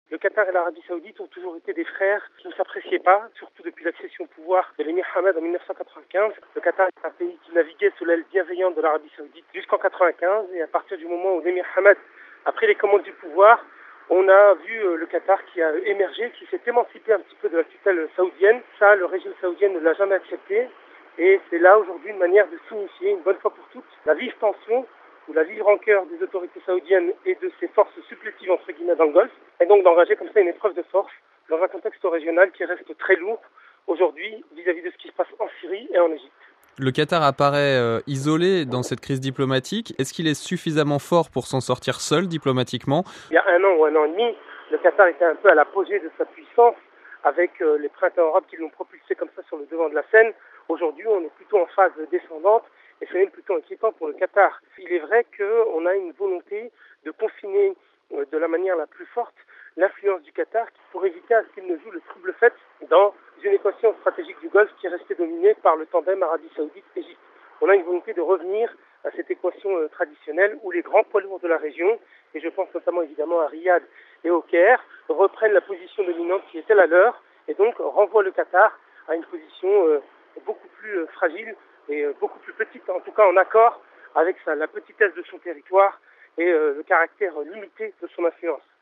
(RV) Entretien - Crise diplomatique sans précédent dans le Golfe Persique : l'Arabie saoudite, les Emirats-Arabes-Unis et Bahreïn ont décidé de rappeler leurs ambassadeurs à Doha, au Qatar, reprochant les « ingérences » qatariennes dans les affaires de ses voisins.